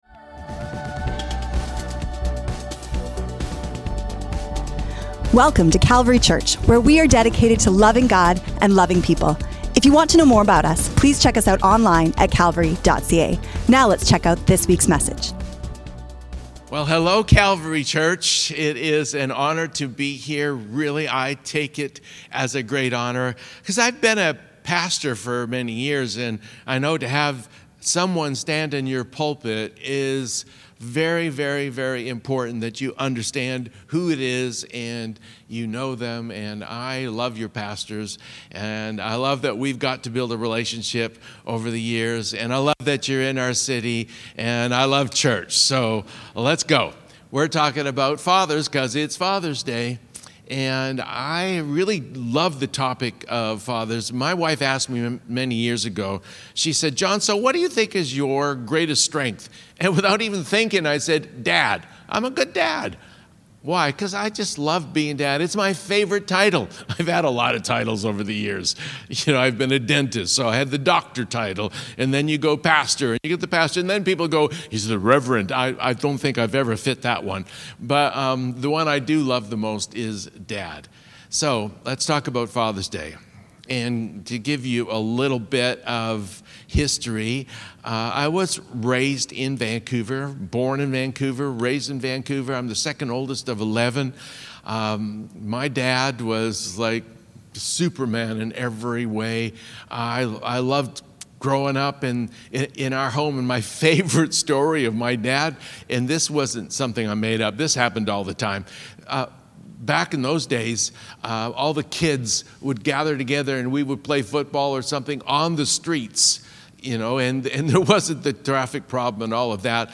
Keys of Fatherhood | Father's Day Service
Current Sermon
Father's Day Guest Speaker